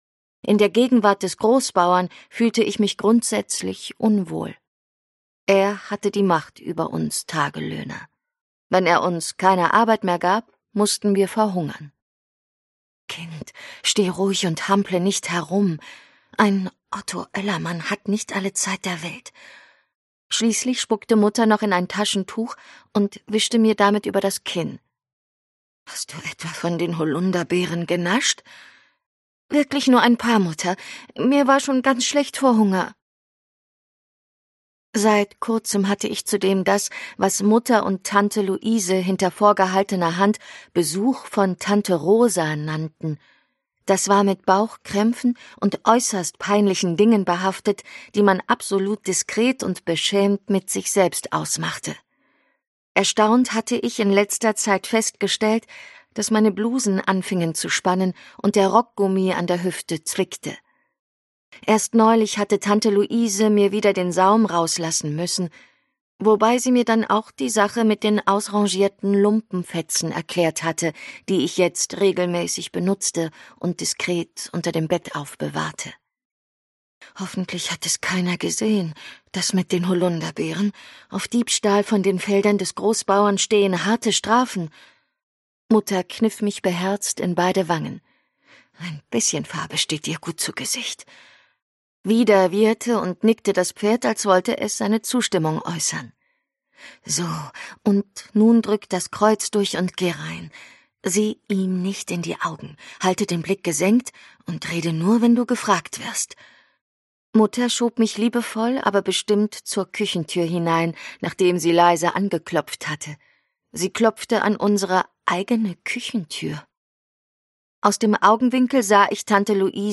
leicht gekürzte Lesung